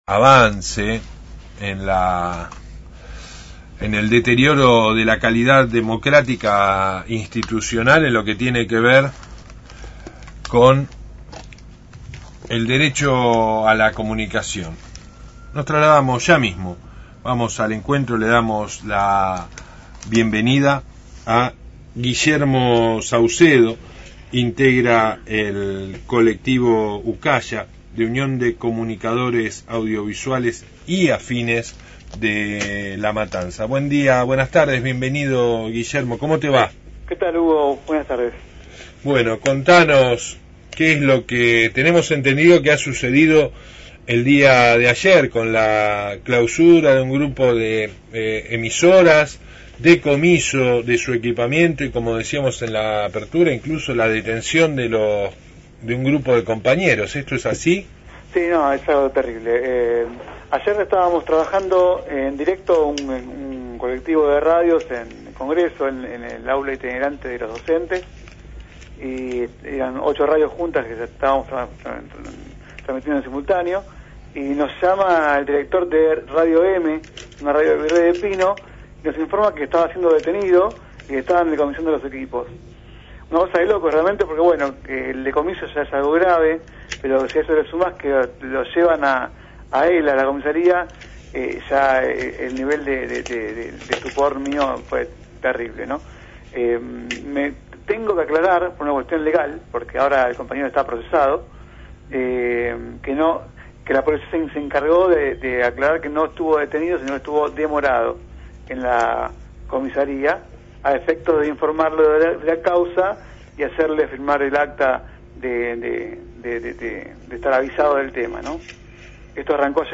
La Unión de Comunicadores Audiovisuales y Afines de La Matanza (Ucaya) denunció que por orden del Ministerio de Comunicaciones, a cargo de Oscar Aguad, se realizaron dos decomisos de equipos a Radio (FM 104.1) de Virrey del Pino, y Fórmula (FM 87.7) de Merlo, además de llevarse detenido a uno de sus responsables. Entrevista